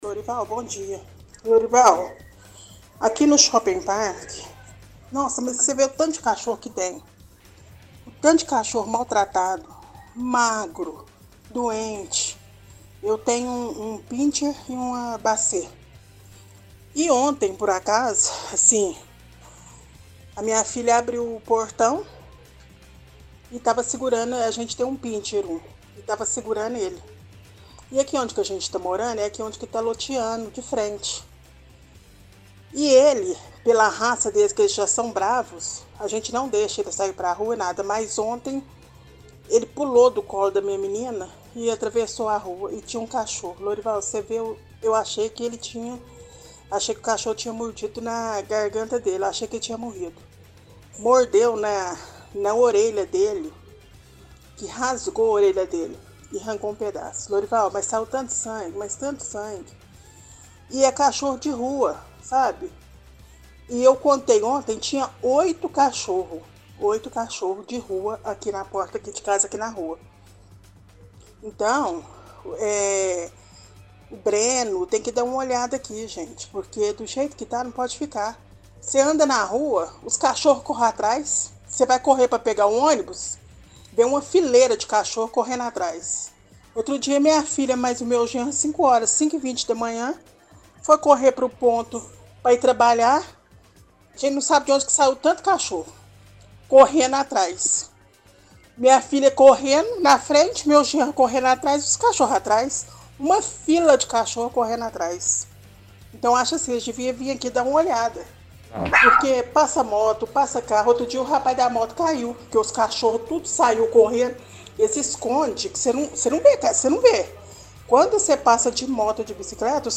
– Ouvinte do bairro Shopping Park reclama da quantidade de cachorros mal tratados, diz que a filha abriu o portão, cachorro da família saiu e os cachorros de rua avançaram e morderam o mesmo, “Tinham 8 cachorros de rua”.